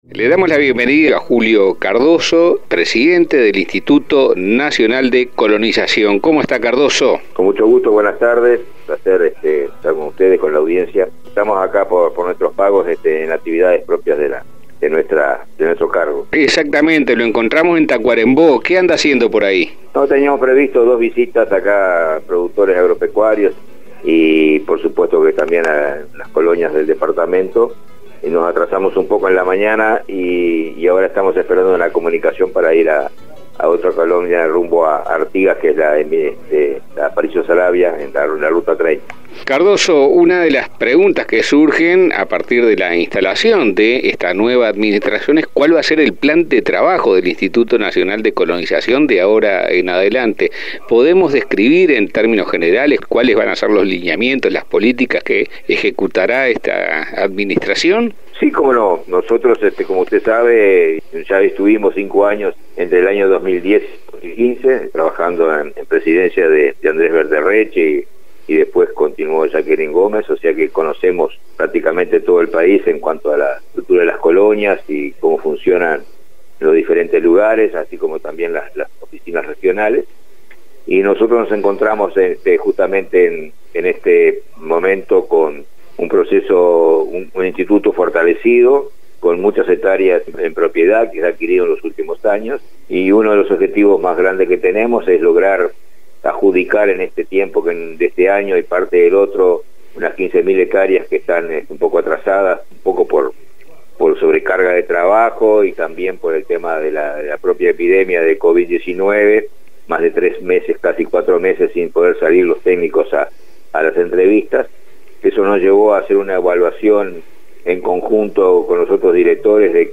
Entrevista-a-Julio-Cardozo-INC.mp3